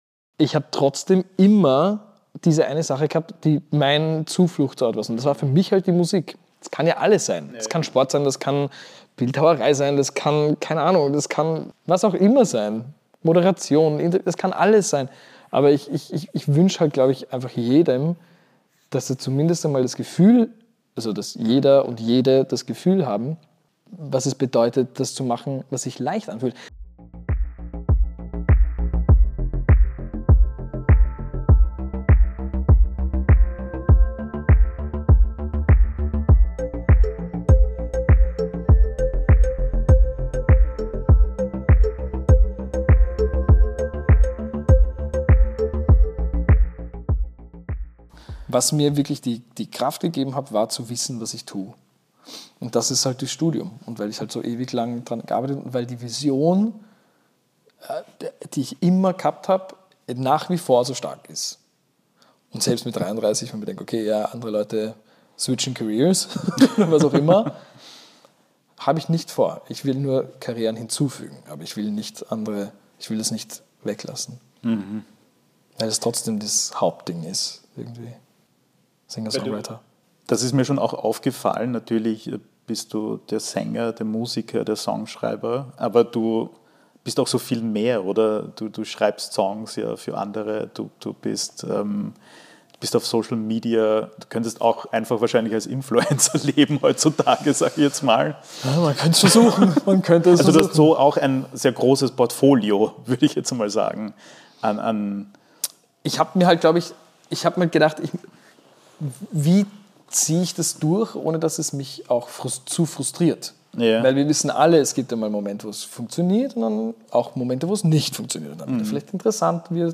Im zweiten Teil unseres Gesprächs geht es um die großen Fragen seiner Karriere und die Suche nach Sinn in der Kunst. Nathan Trent spricht über seine Arbeitsethik, warum Musik für ihn immer auch Zuflucht ist und wie lange ihn der Song Contest noch verfolgt hat.